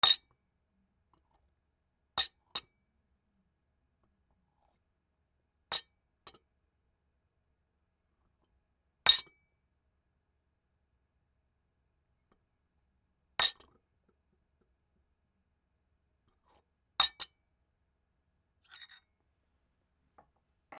ティーカップ ソーサラー 置く(スプーンあり)